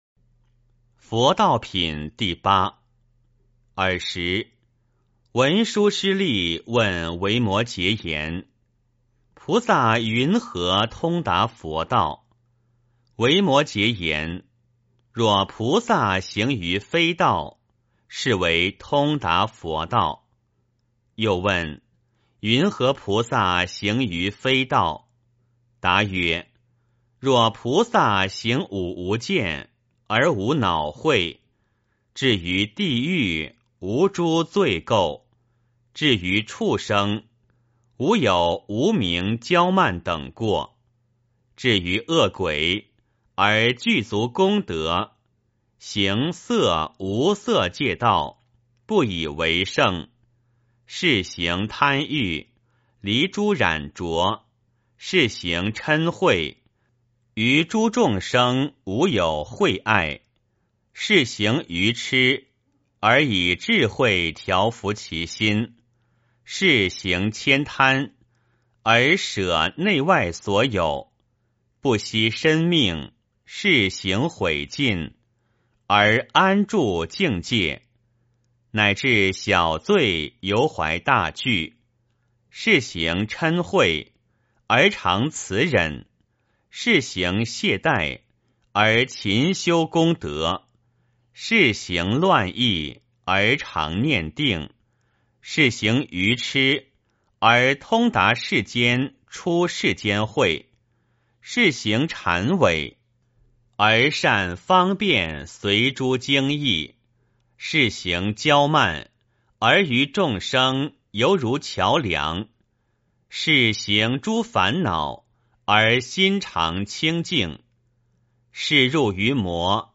维摩诘经-佛道品第八 - 诵经 - 云佛论坛